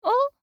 알림음(효과음) + 벨소리
알림음 8_WomanOh4.mp3